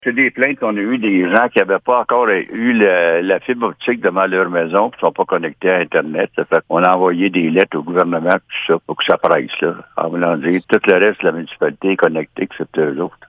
Selon le maire de Messines, Ronald Cross, de telles disparités entre les résidents d’une même municipalité ne devraient pas exister :